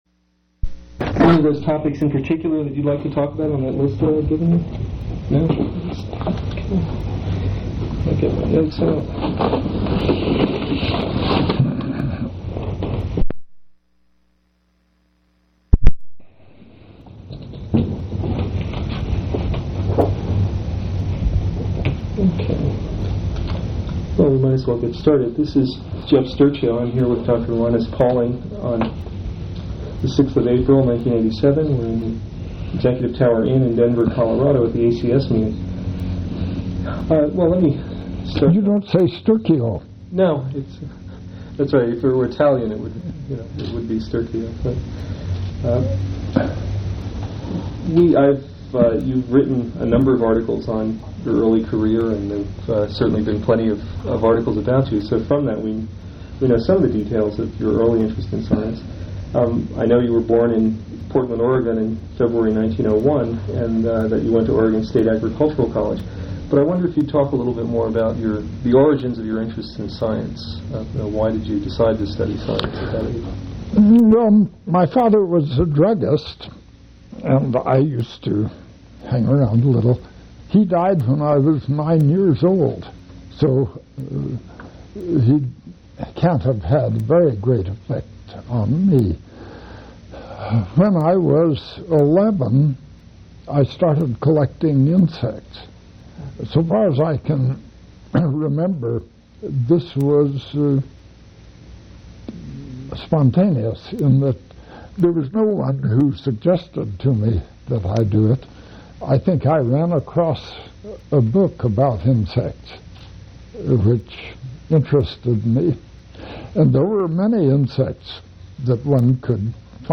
Oral history interview with Linus C. Pauling